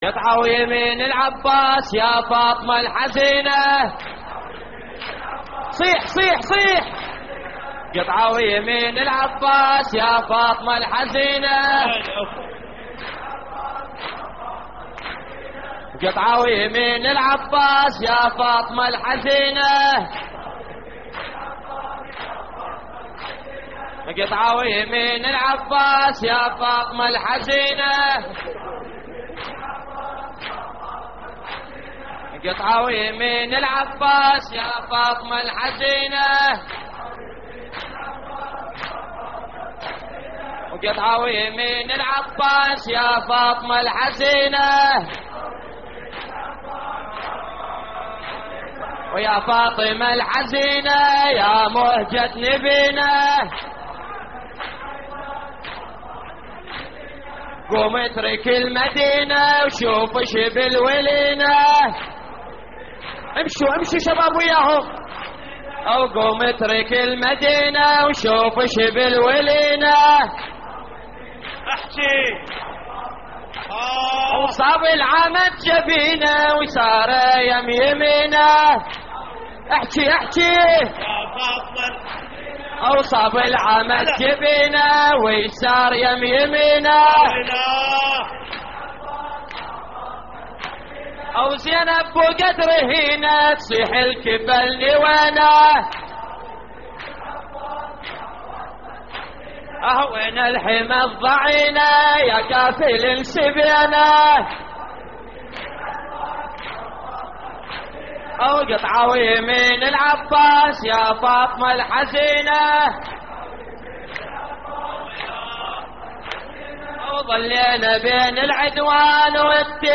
رداديات
اللطميات الحسينية